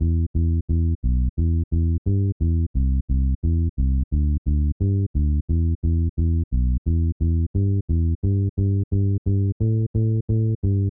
低音 大规模的铁 175bpm
描述：um Bass低音，或任何用于175 / 87.5 bpm的用途
Tag: 175 bpm Drum And Bass Loops Bass Guitar Loops 1.85 MB wav Key : Unknown